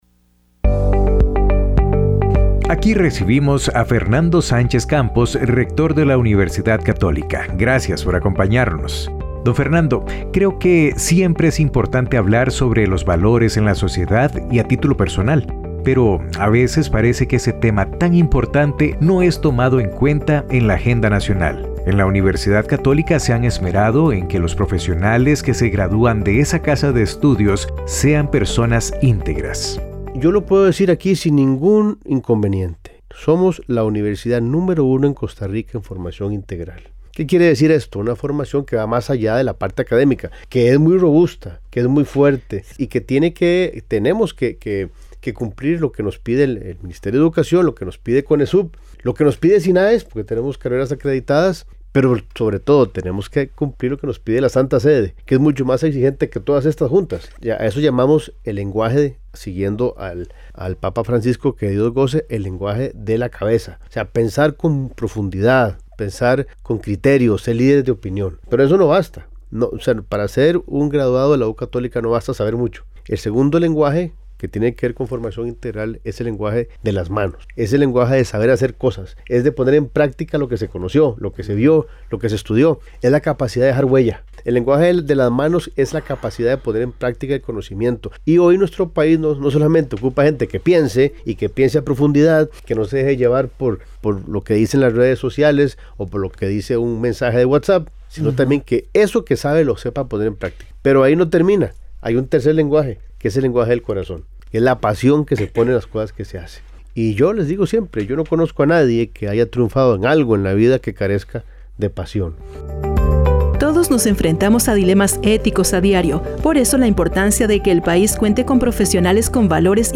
Cápsulas